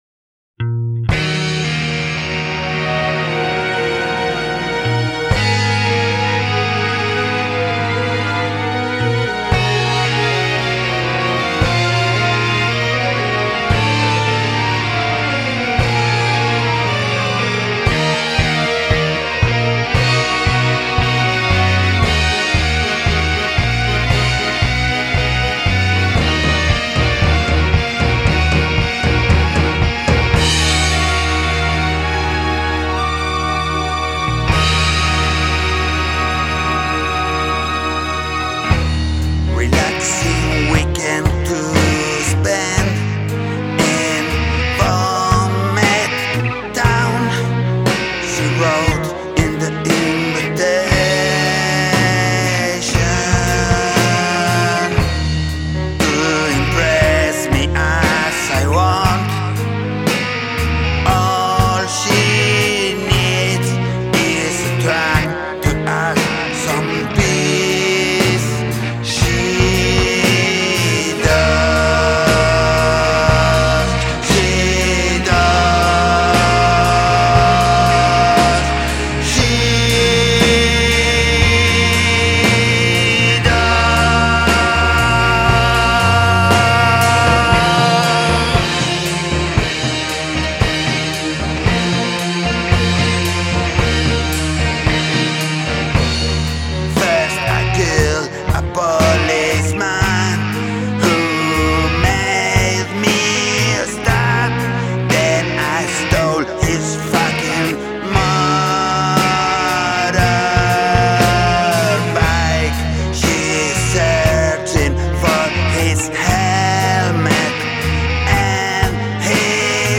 Epic story song
Is it the dinosaur feet beat? The synth lead?